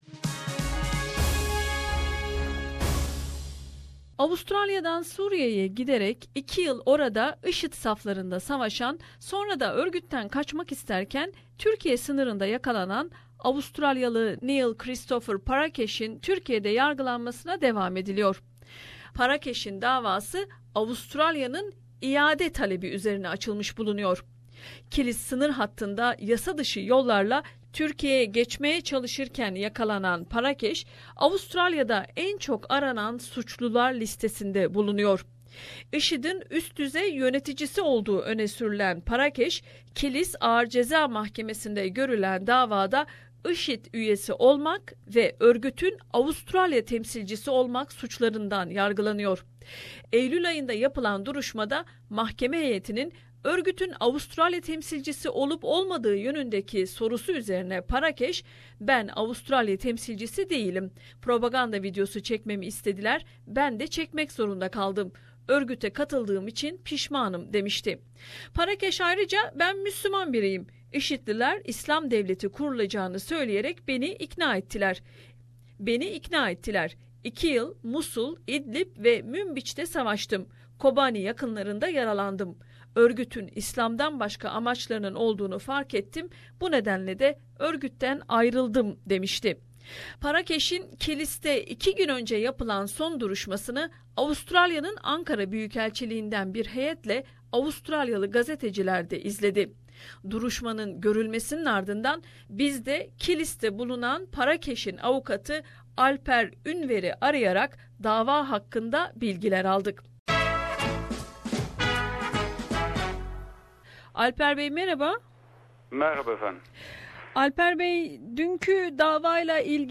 bir söyleşi yaptık